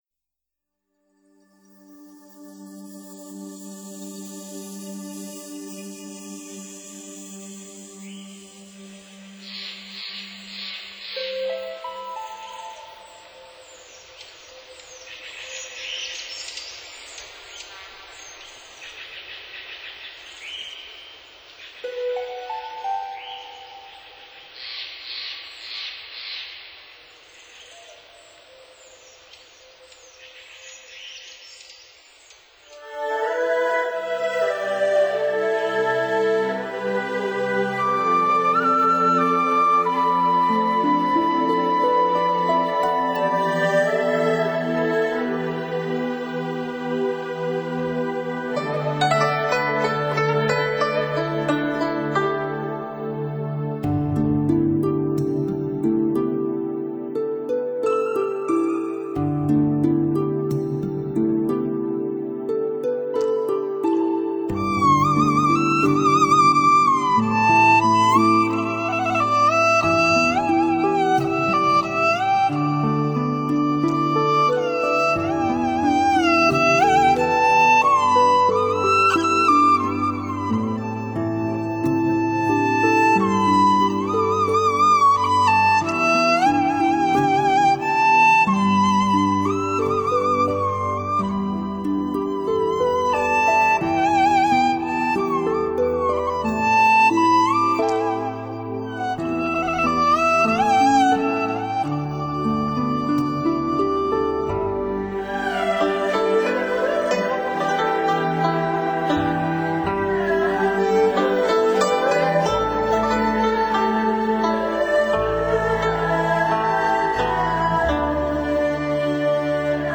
演奏风格与乐曲处理上都有全新演绎，古典韵致的拉弦名典与现代电子音乐的完美结合，创造出别致悠远的意境。